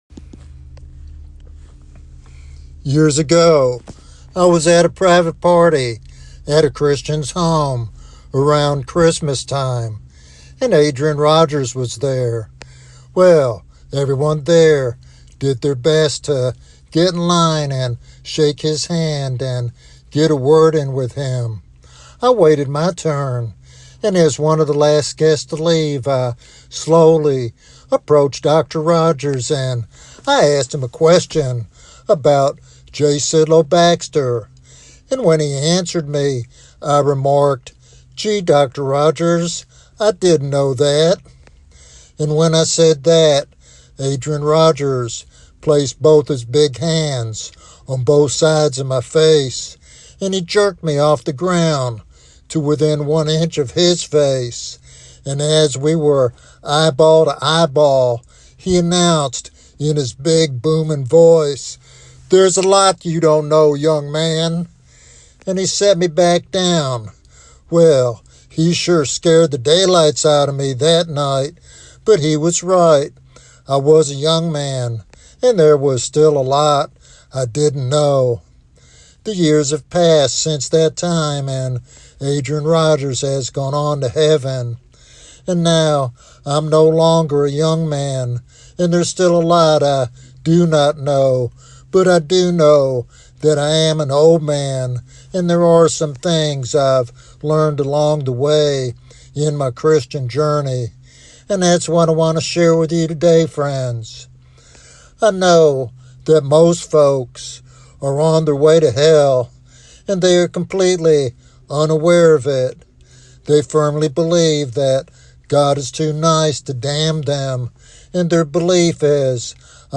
This devotional sermon encourages listeners to live with eternal perspective and accountability.
Sermon Outline